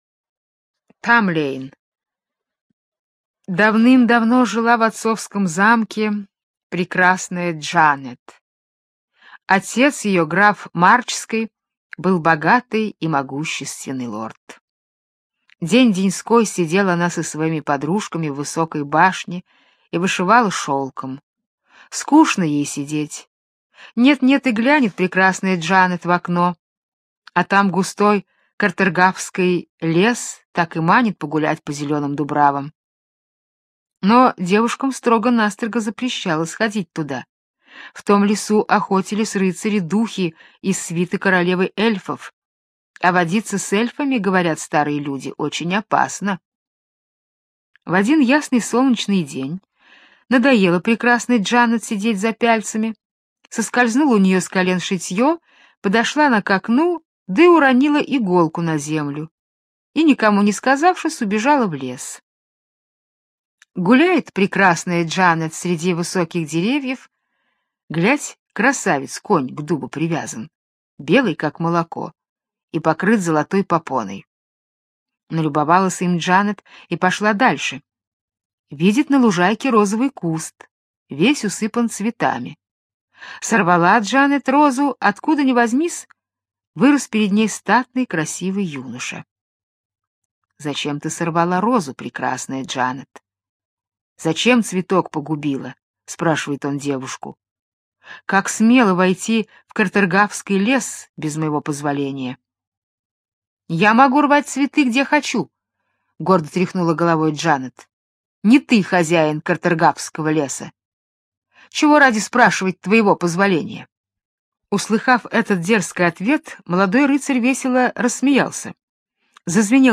Тамлейн - британская аудиосказка - слушать онлайн